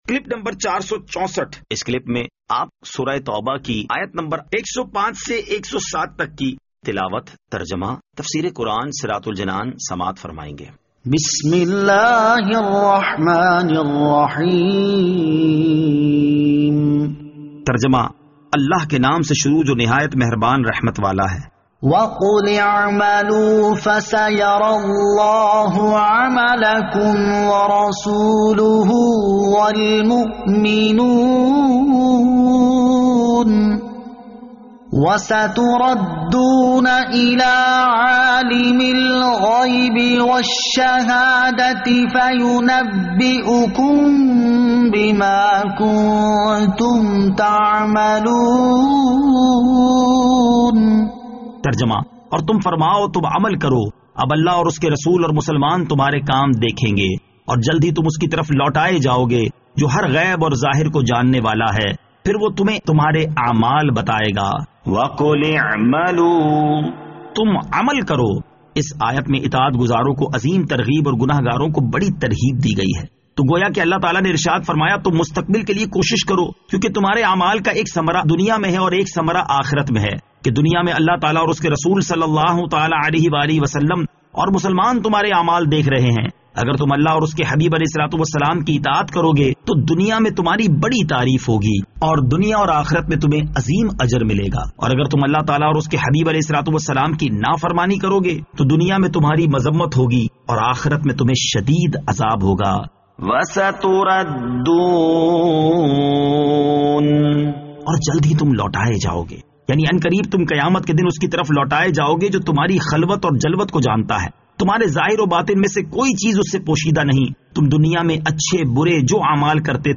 Surah At-Tawbah Ayat 105 To 107 Tilawat , Tarjama , Tafseer